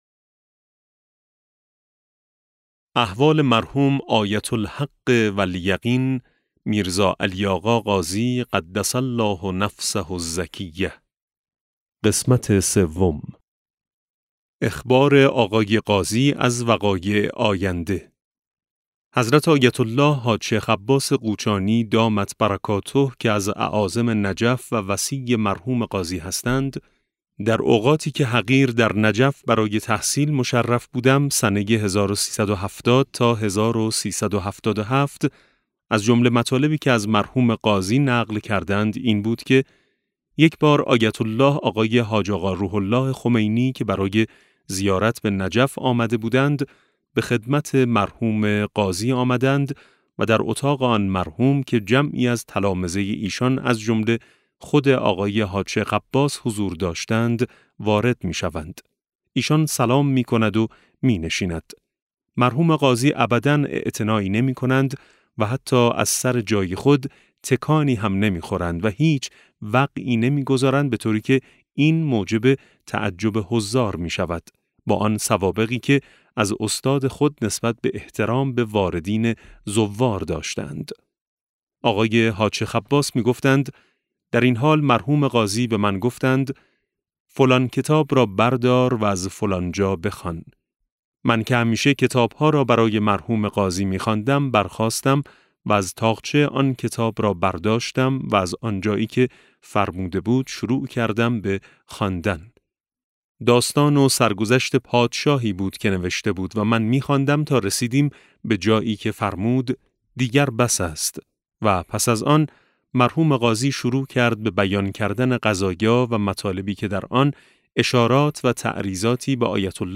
مطلع انوار ج2 | احوالات آیت الحق و الیقین آقا سید علی قاضی (بخش سوم) - کتاب صوتی - کتاب صوتی مطلع انوار ج2 - علامه طهرانی | مکتب وحی